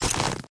repair_wolf2.ogg